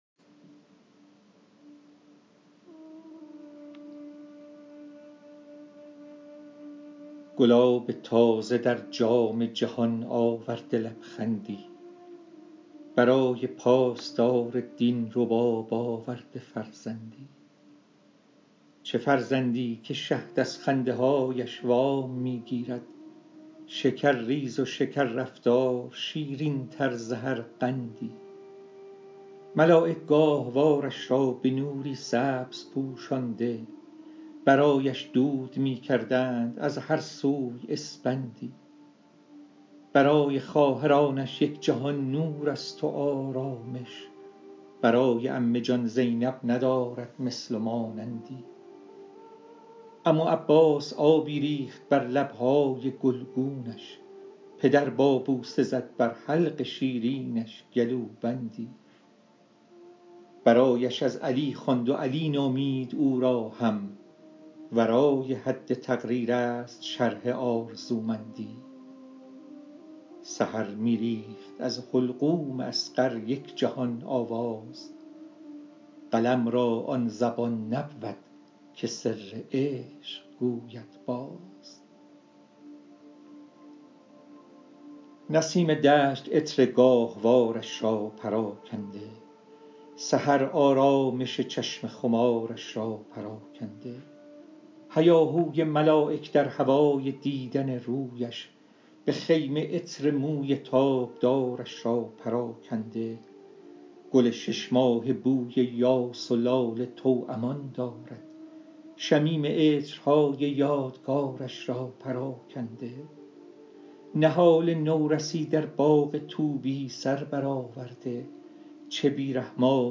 دکلمه